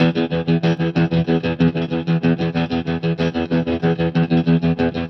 Trem Trance Guitar 01b.wav